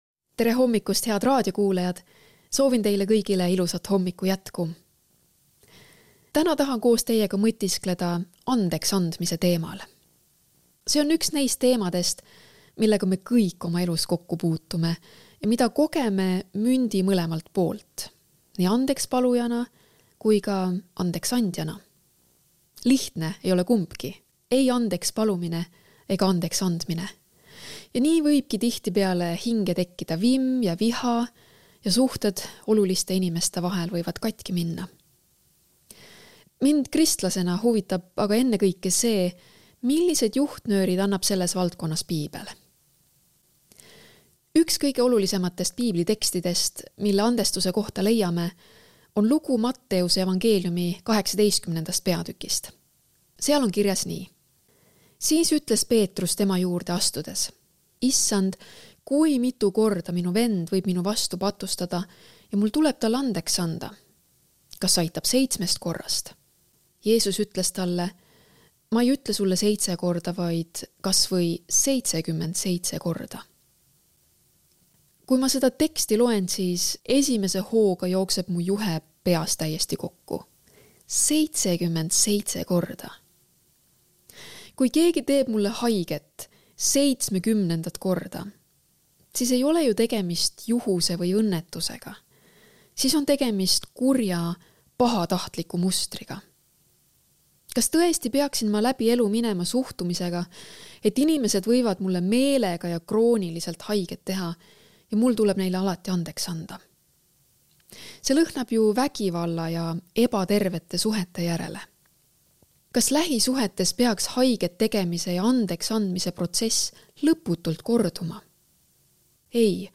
hommikupalvus ERR-is 30.06.2024